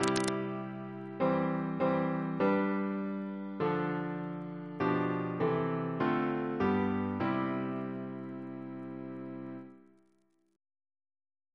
Single chant in C Composer: Thomas Attwood Walmisley (1814-1856), Professor of Music, Cambridge Note: first half of a double Reference psalters: ACB: 163; CWP: 246; RSCM: 153